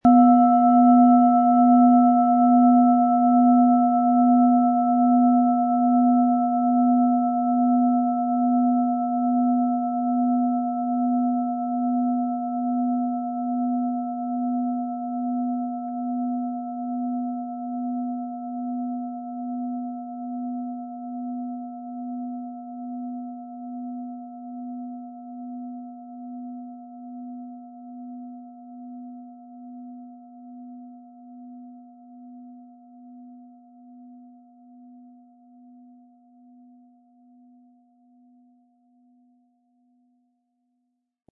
Planetenschale® Herzbereich öffnen & Freudig sein mit Delfin-Ton, Ø 15,2 cm, 500-600 Gramm inkl. Klöppel
Im Audio-Player - Jetzt reinhören hören Sie genau den Original-Klang der angebotenen Schale. Wir haben versucht den Ton so authentisch wie machbar aufzunehmen, damit Sie gut wahrnehmen können, wie die Klangschale klingen wird.
Ein die Schale gut klingend lassender Schlegel liegt kostenfrei bei, er lässt die Planetenklangschale Delfin harmonisch und angenehm ertönen.
MaterialBronze